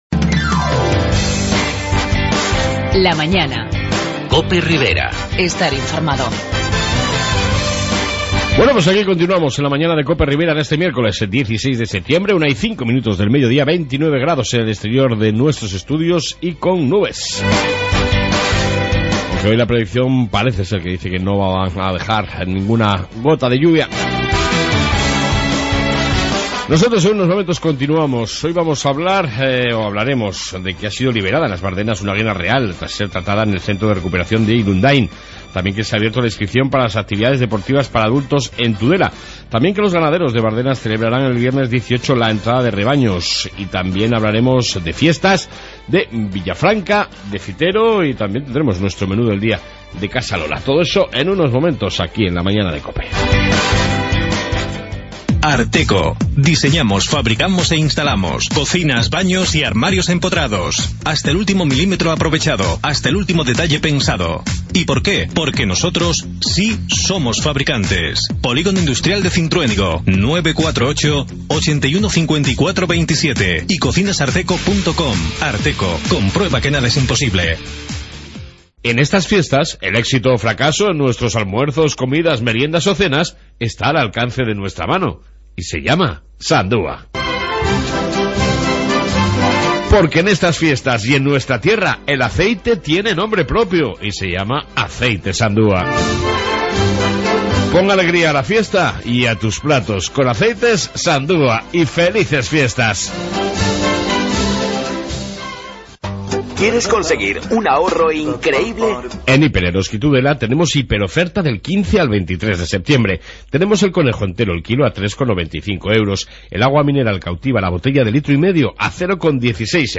AUDIO: En esta 2 parte Noticias Riberas, Entrevista con Ecologistas en accón sobre un nuevo proyecto de parque Eólico en la Ribera y Fiestas Riberas